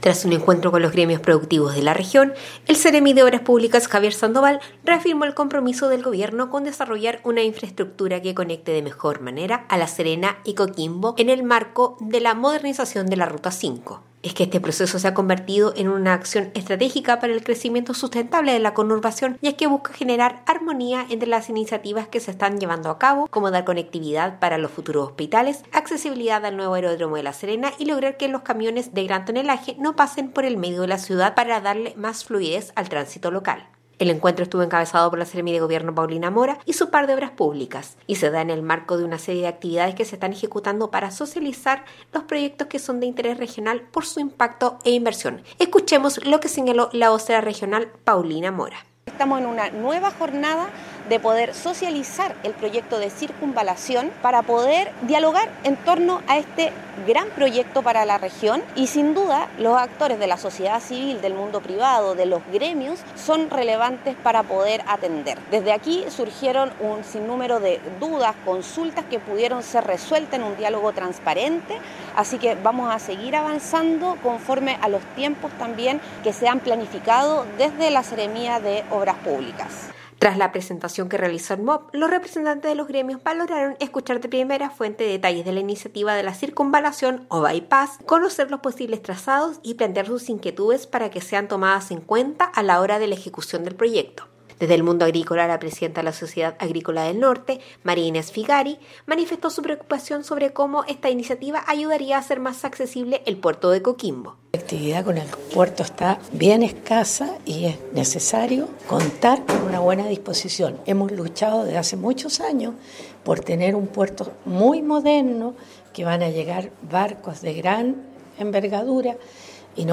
DESPACHO-REUNION-CIRCUNVALACION-CON-GREMIOS.mp3